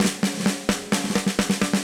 AM_MiliSnareB_130-01.wav